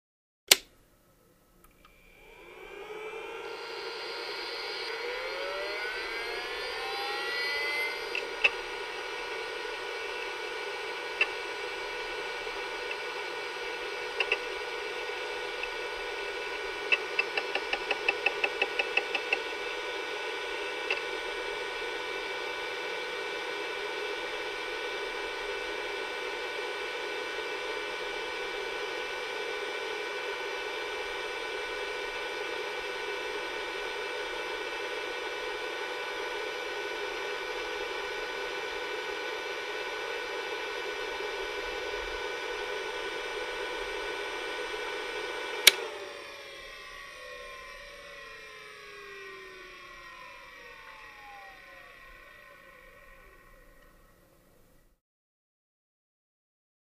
Hard Drive On | Sneak On The Lot
Ext. Hard Drive; On / Steady / Off; 2.0 Gigabyte External SCSI Hard Drive; Power On / Drive And Fan Spin Up / Drive Access / Steady Fan Idle / Power Off / Drive And Fan Spin Down, Close Perspective.